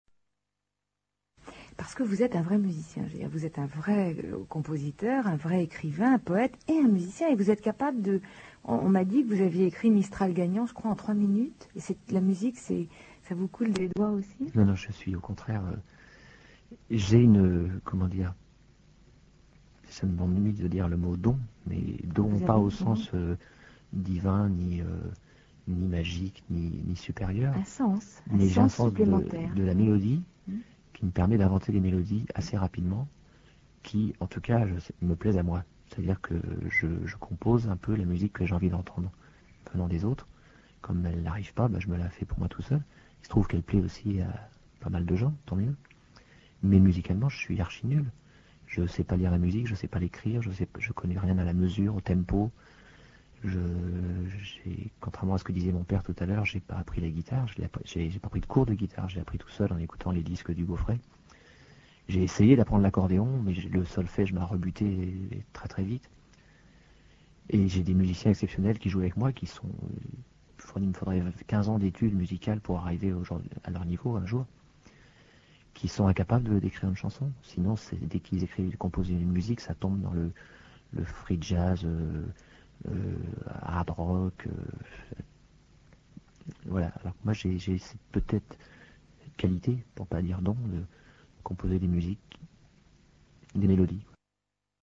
Interview de Renaud à RTL le 9 octobre 1989